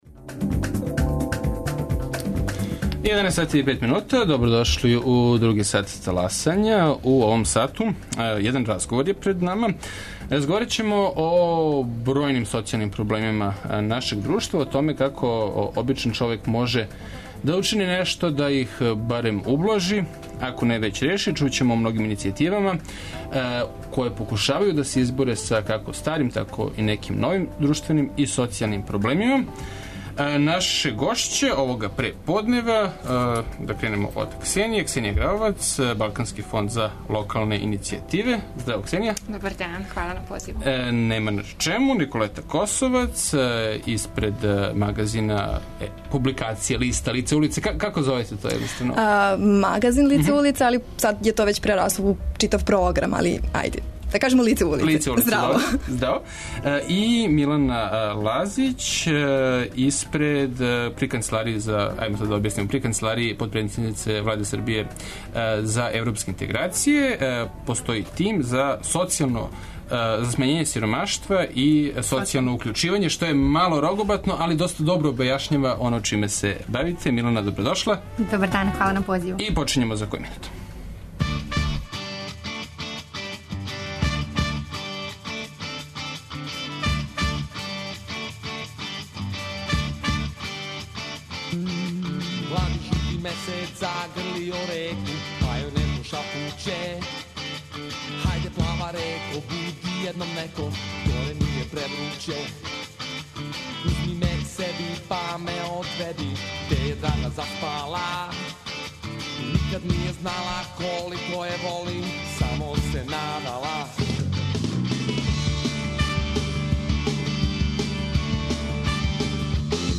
При Влади Србије постоји тим за социјално укључивање и смањење сиромаштва, а наше гошће су младе активисткиње које раде у тиму или сарађују са њим, и у сталној су потрази за одговорима на проблеме најугроженијих друштвених група.